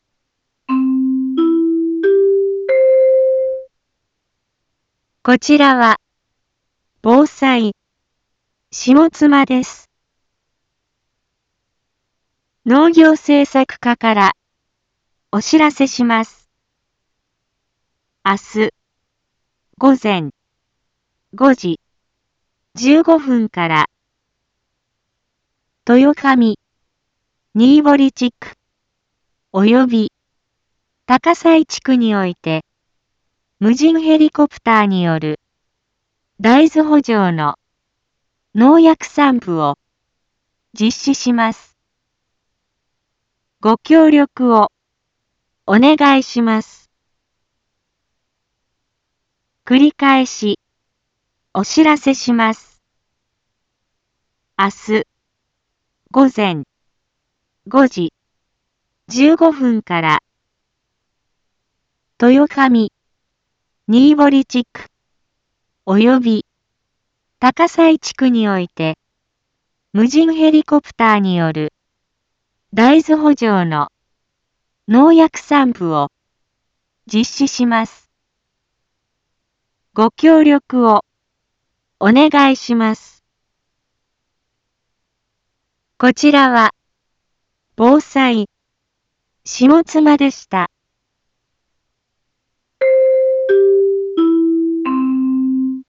一般放送情報
Back Home 一般放送情報 音声放送 再生 一般放送情報 登録日時：2024-08-28 12:31:38 タイトル：大豆の無人ヘリによる空中防除 インフォメーション：こちらは、ぼうさい、しもつまです。